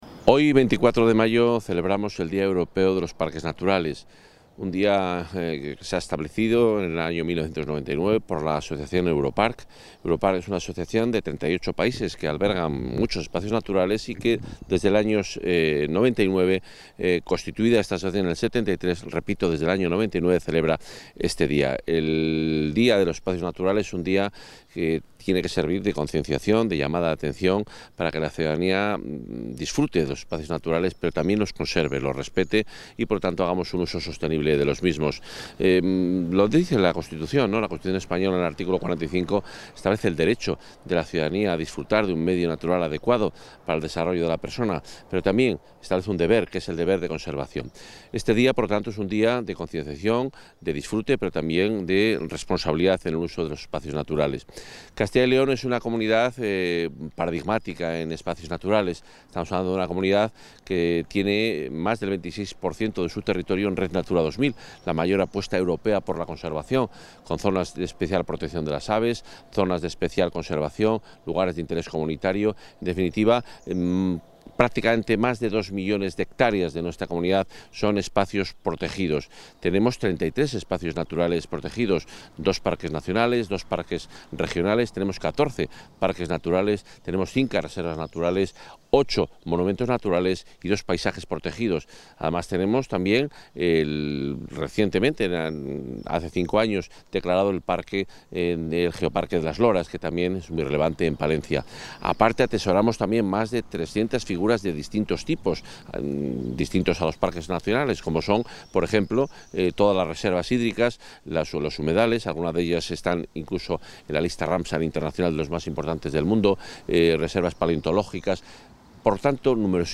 Declaraciones del consejero.